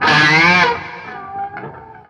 DIVEBOMB 5-R.wav